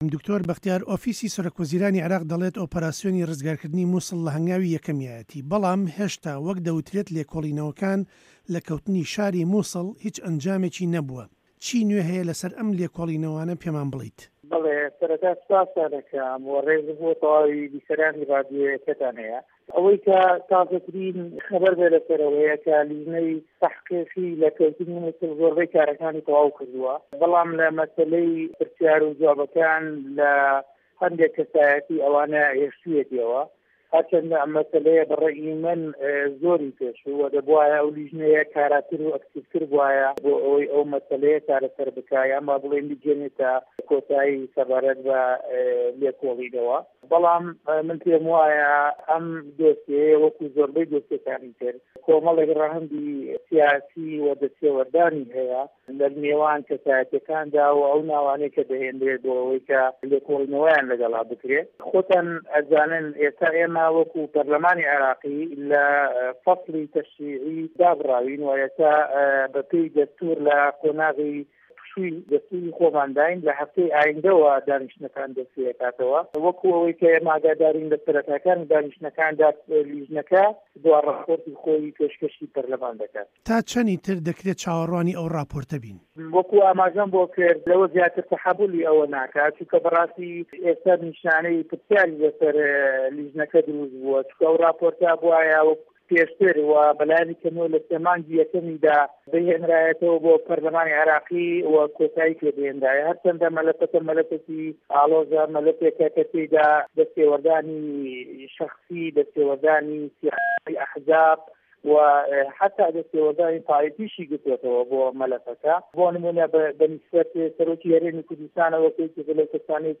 ئه‌مه‌ ته‌وه‌ره‌ی گفتووگۆی ده‌نگی ئه‌مریکایه‌ له‌گه‌ڵ دکتۆر به‌ختیار شاوه‌یس ئه‌ندامی په‌رله‌مانی عێراق.
گفتووگۆ له‌گه‌ڵ دکتۆر به‌ختیار شاوه‌یس